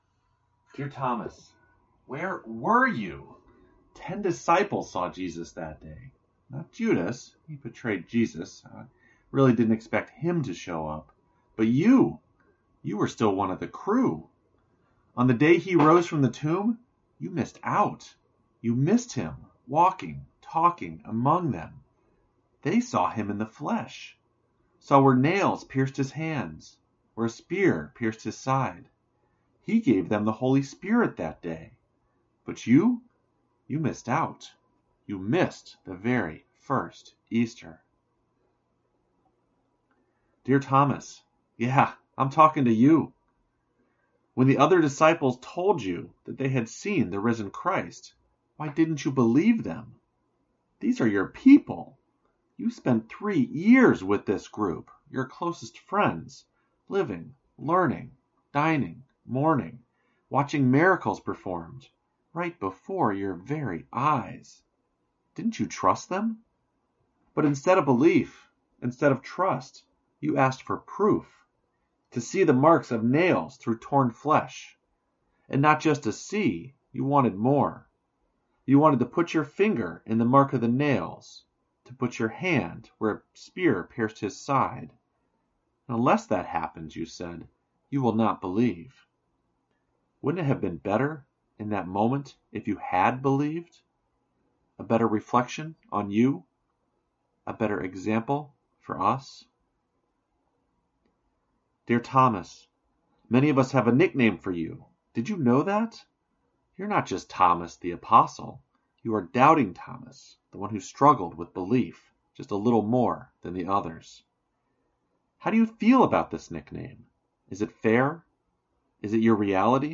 It was given at Bar Church on the stage of The Kelsey Theater, a truly unique setting and way of doing church I’ll blog on shortly.